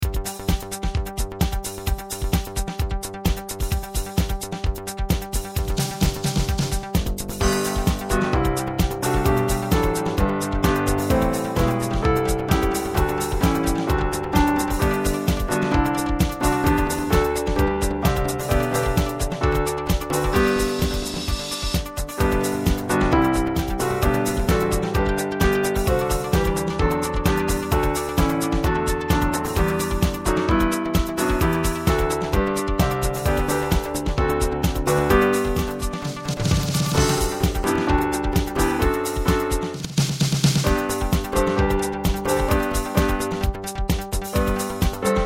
Sydney Girls' High School Song (Techno)